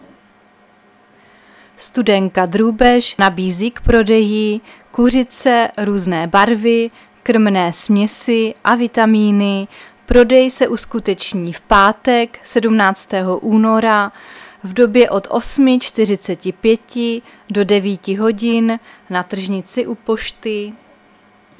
Záznam hlášení místního rozhlasu 15.2.2023